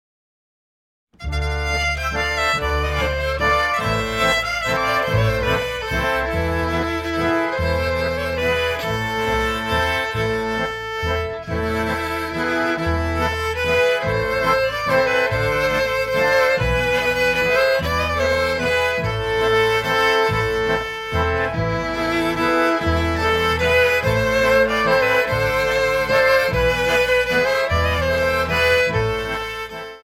soittavat kansanmusiikkia korvakuulolta tällä levyllä
kansansävelmä